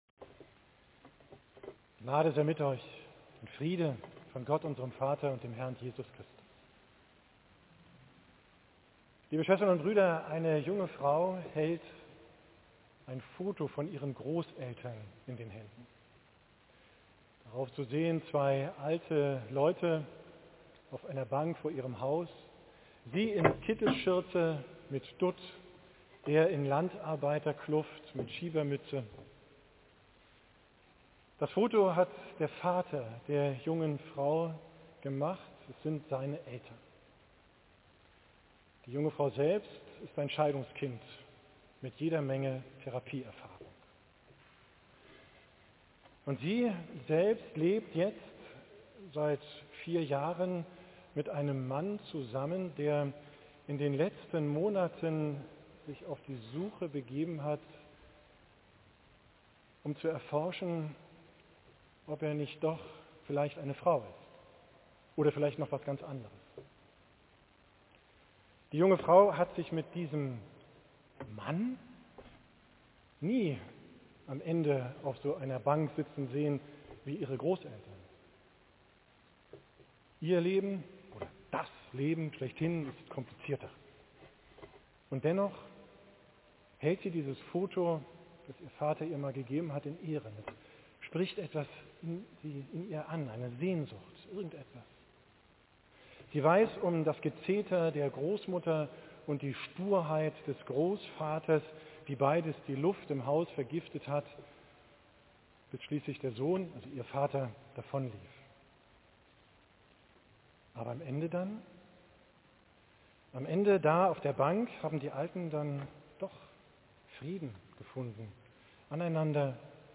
Predigt vom 20.